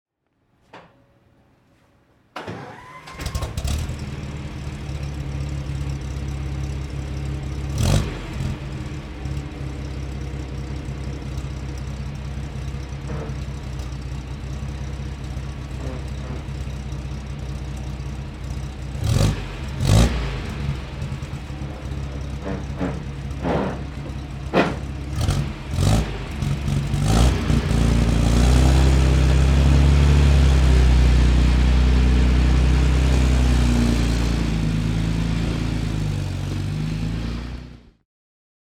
Hispano-Suiza H6B Spohn (1927) - Starten und Leerlauf
Hispano_Suiza_H6B.mp3